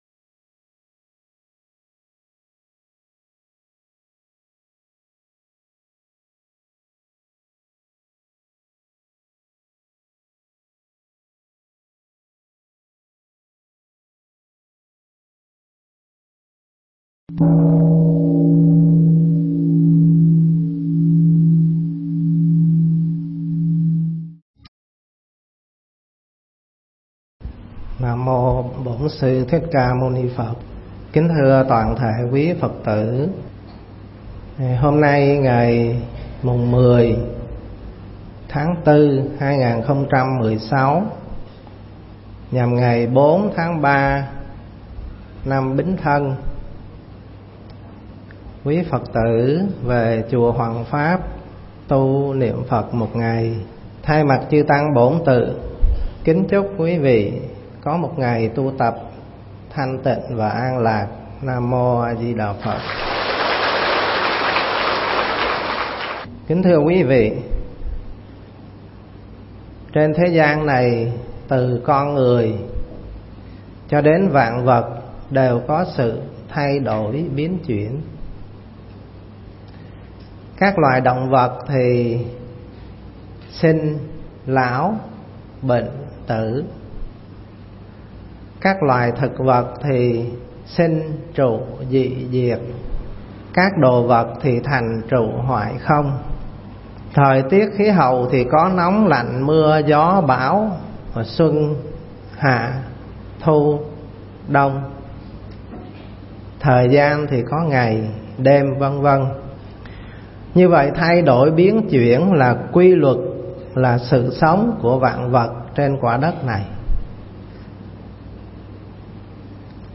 Nghe Mp3 thuyết pháp 8 Pháp Thế Gian
mp3 pháp thoại 8 Pháp Thế Gian